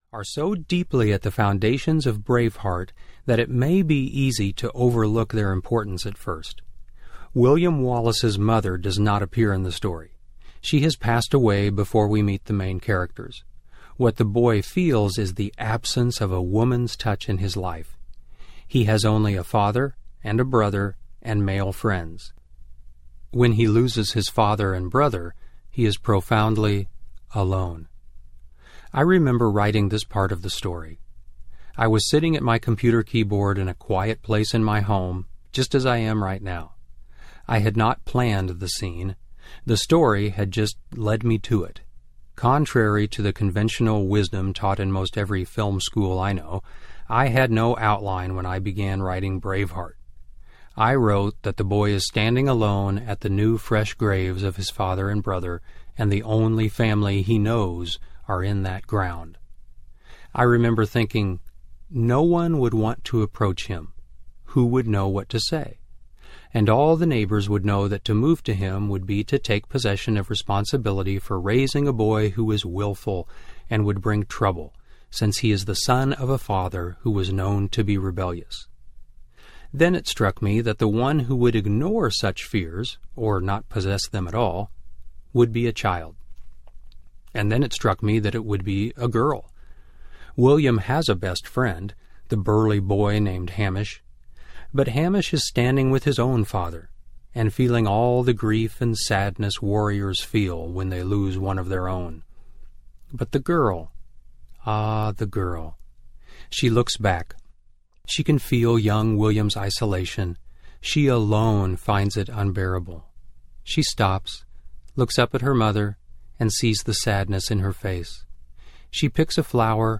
Living the Braveheart Life Audiobook
Narrator